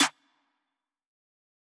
UZI SNARE.wav